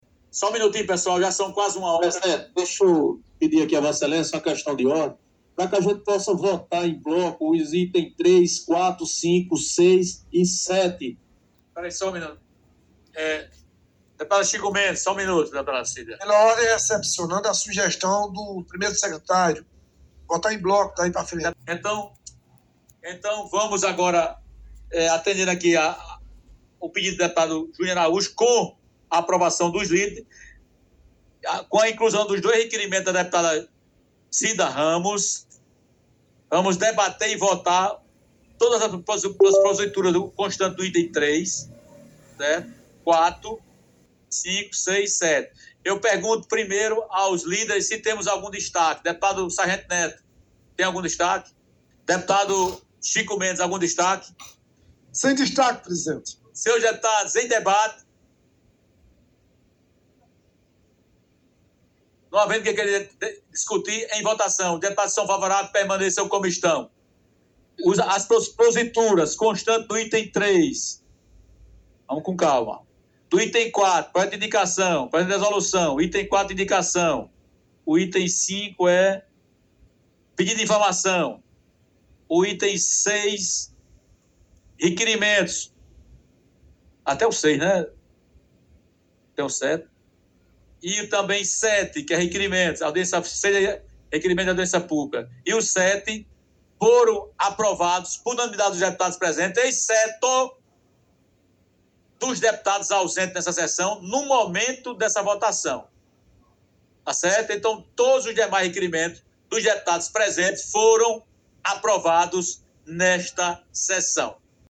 No momento da votação, o deputado Júnior Araújo apresentou Questão de Ordem propondo que os itens 3, 4, 5, 6 e 7 fossem apreciados em bloco, ou seja, todos de uma só vez.
O presidente da Assembleia, deputado Adriano Galdino perguntou aos líderes se ambos concordavam com a sugestão apresentada por Júnior Araújo.
Confira momento da votação:
SESSAO-ORDNARIA.mp3